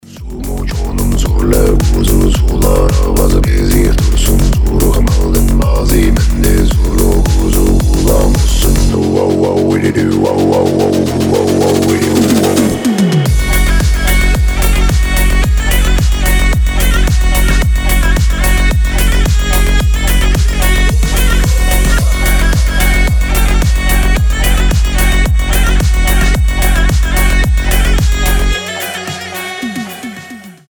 красивый мужской голос
psy-trance
турецкие
дудка
этнические